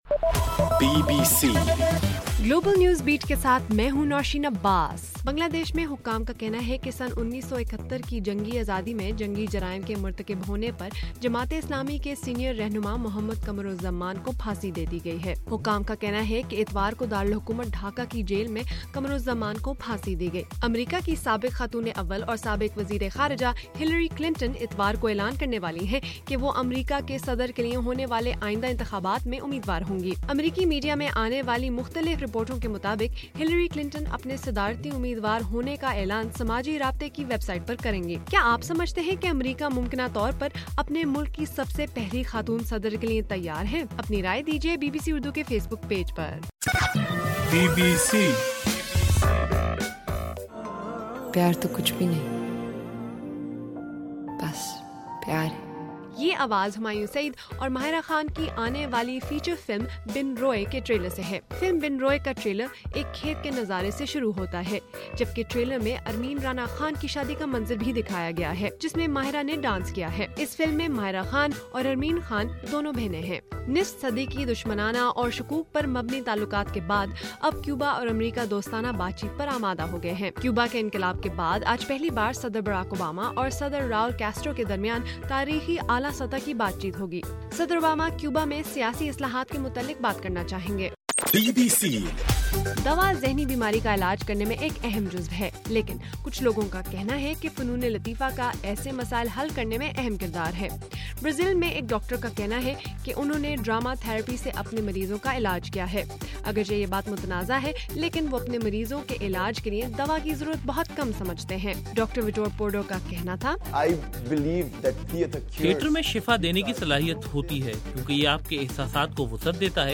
اپریل 11: رات 12 بجے کا گلوبل نیوز بیٹ بُلیٹن